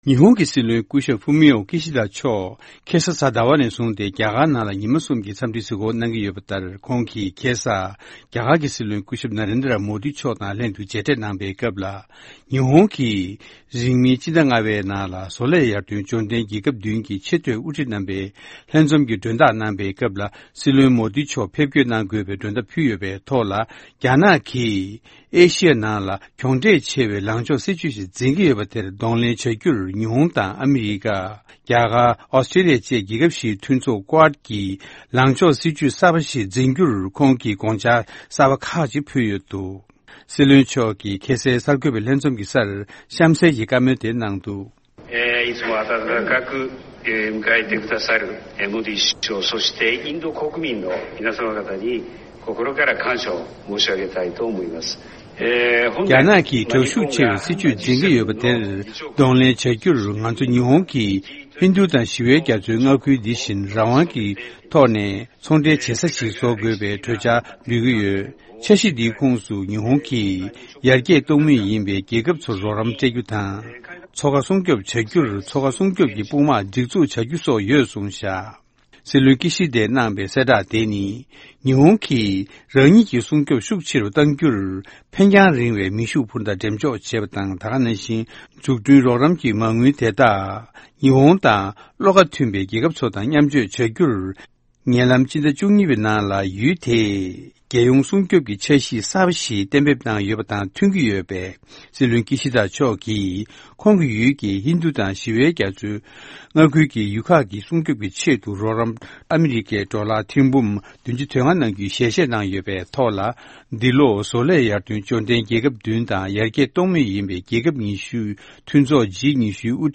ཕབ་བསྒྱུར་དང་སྙན་སྒྲོན་ཞུས་གནང་བ་འདིར་འཁོད་ཡོད།